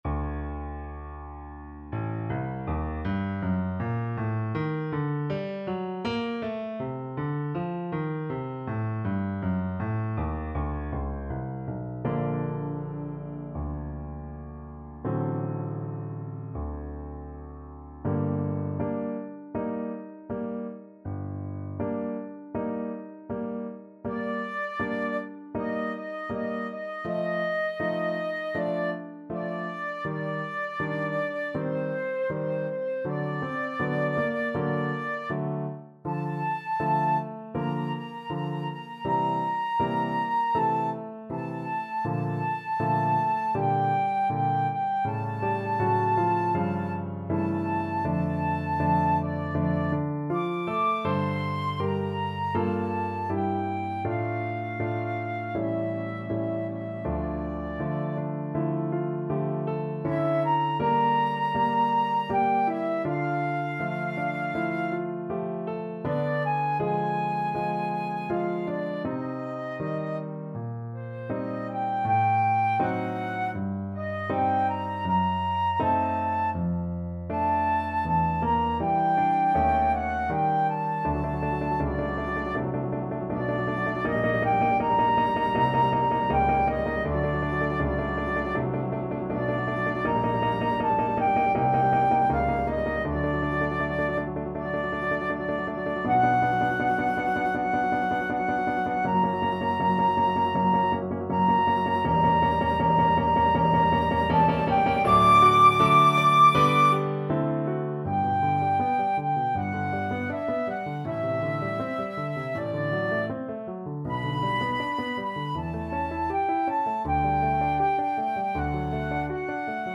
(Repentir) Flute version
G minor (Sounding Pitch) (View more G minor Music for Flute )
~ = 100 Molto moderato =80
Classical (View more Classical Flute Music)